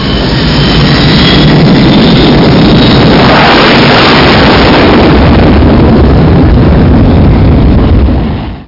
Amiga 8-bit Sampled Voice
airstrike.mp3